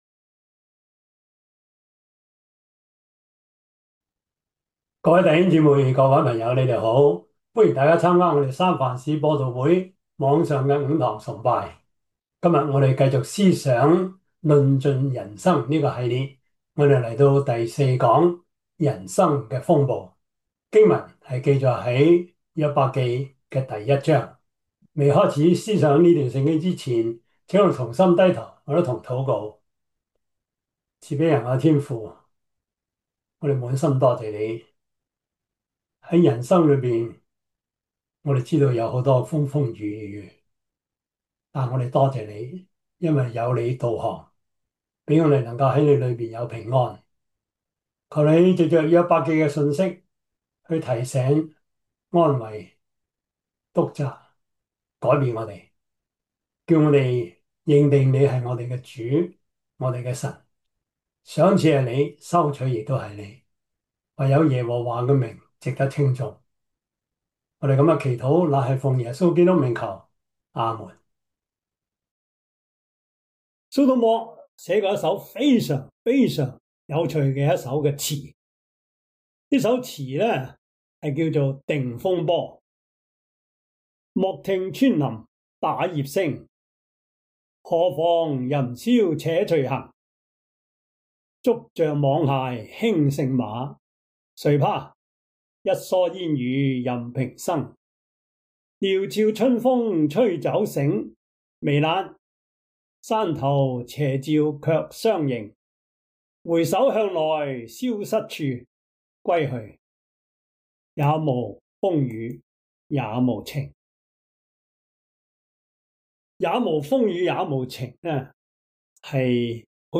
約伯記 1 Service Type: 主日崇拜 約伯記 1 Chinese Union Version
Topics: 主日證道 « 學基督的忍耐 假冒為善口不對心的敬拜生活 »